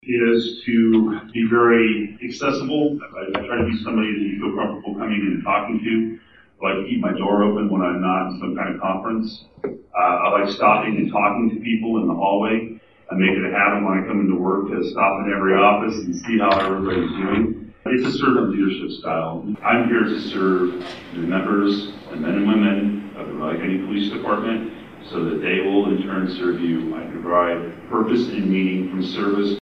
The Riley County Law Board held a three hour public forum at the Manhattan Fire Department Headquarters.